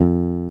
Instrument from Mario Paint